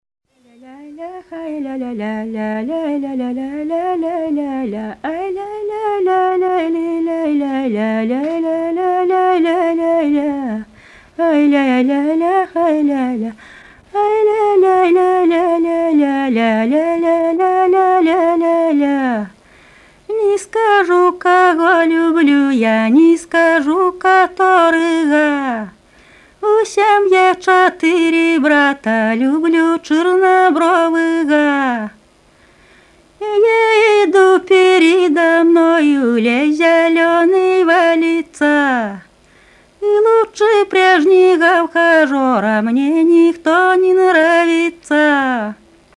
ОБЪЕКТЫ НЕМАТЕРИАЛЬНОГО КУЛЬТУРНОГО НАСЛЕДИЯ ПСКОВСКОЙ ОБЛАСТИ
Частушки "под язык", исп.
Усвятский р-н